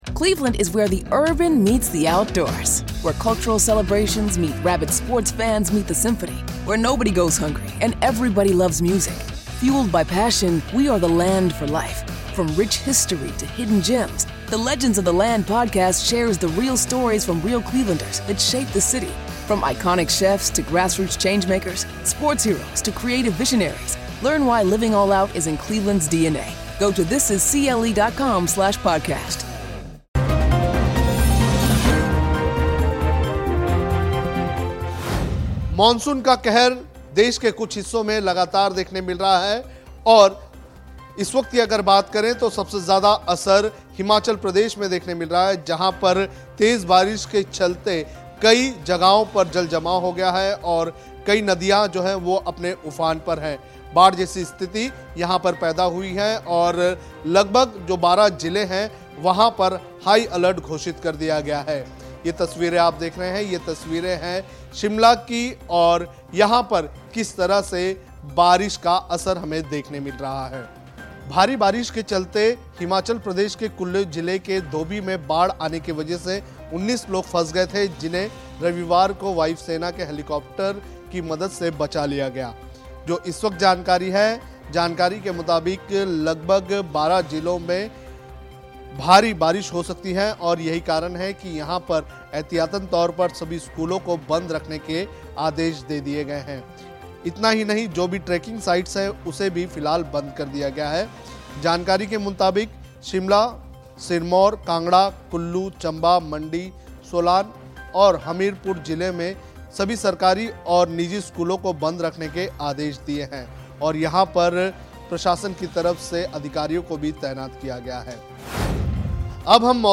न्यूज़ रिपोर्ट - News Report Hindi